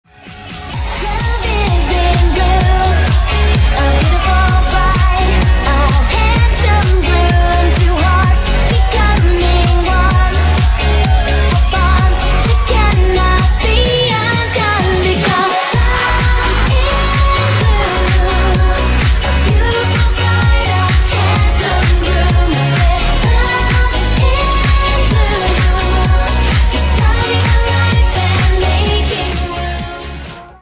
• Качество: 320, Stereo
женский вокал
веселые
добрые
ремикс
танцевальные
детские